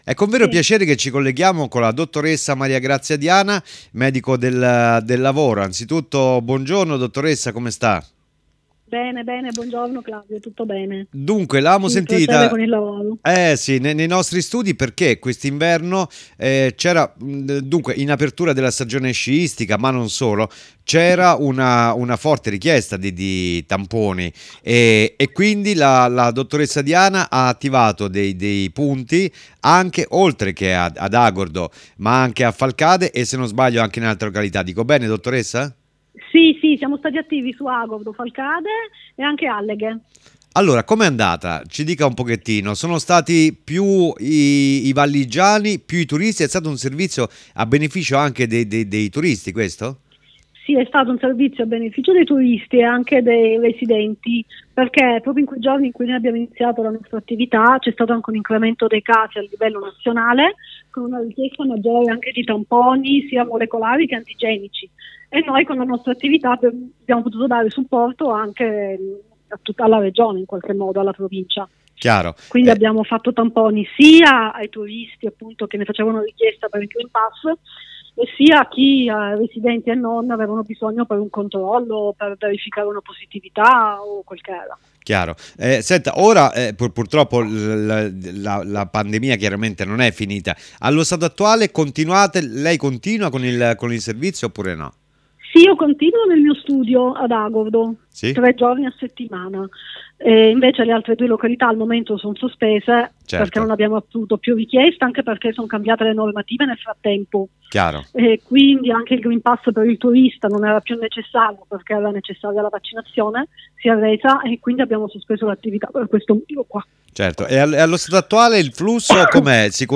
IERI ALLA RADIO
ZERO TERMICO E’ UN VIAGGIO ALLA SCOPERTA DEL NOSTRO TERRITORIO, DALLA VOCE DEI PROTAGONISTI.
medico del lavoro